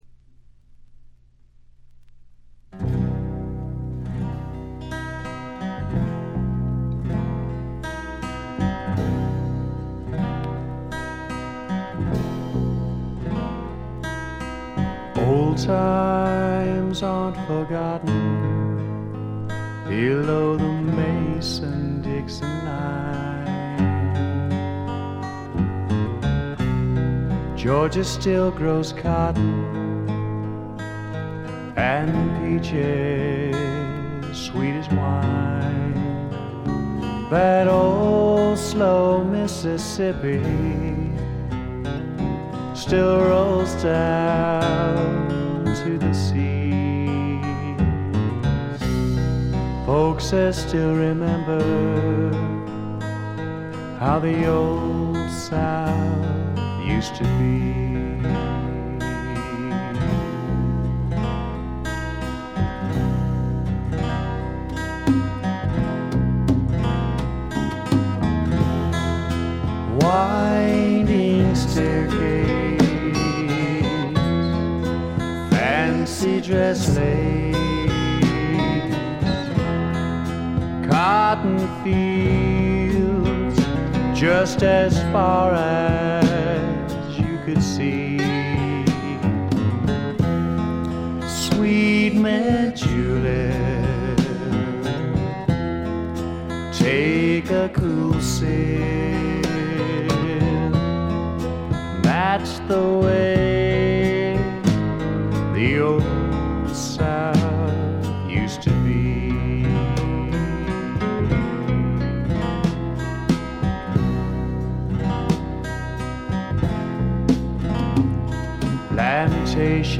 ほとんどノイズ感無し。
カントリー風味の曲とかファンキーな曲とかもありますが、クールでちょいメロウな曲調が特に素晴らしいと思います。
試聴曲は現品からの取り込み音源です。
Recorded at - Real To Reel , Garland, Texas